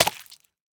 sounds / mob / dolphin / eat2.ogg
eat2.ogg